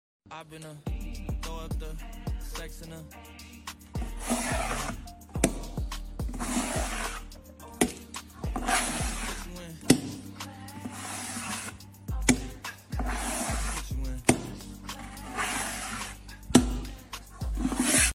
Scratch, Scrape, It's Too Sound Effects Free Download